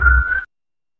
不協和音
clattering.mp3